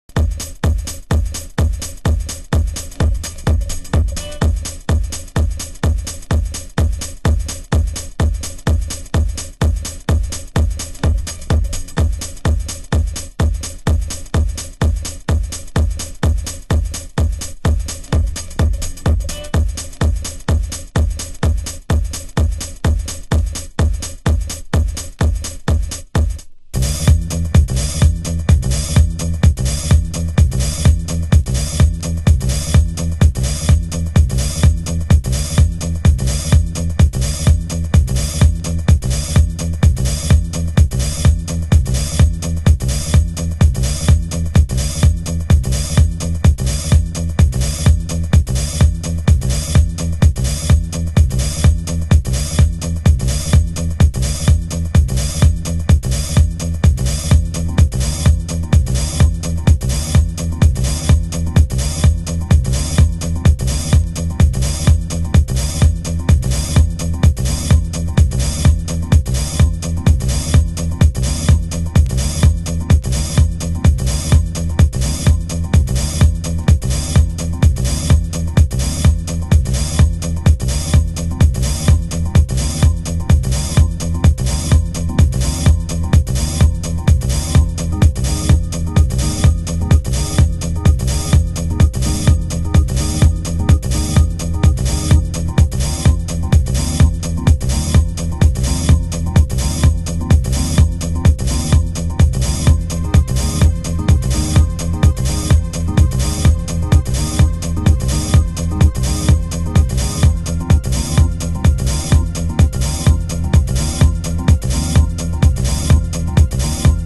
盤質：軽いスレ有/少しチリノイズ有